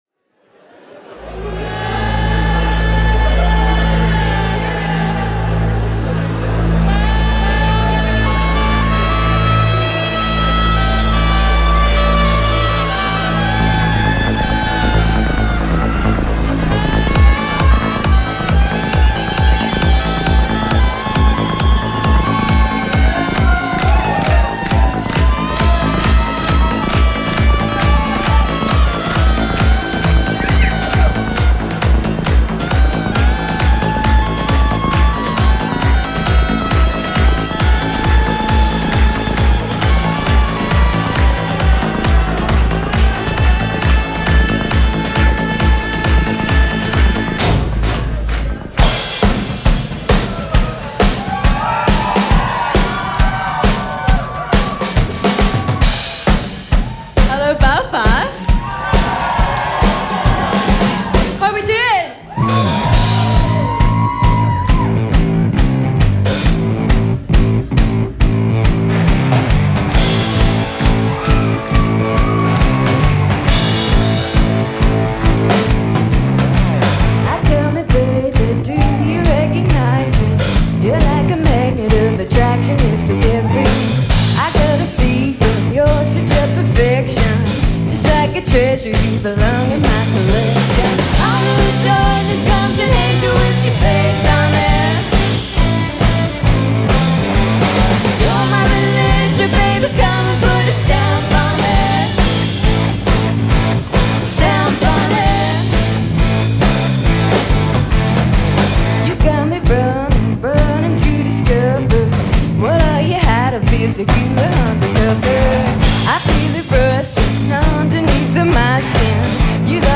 lead-guitar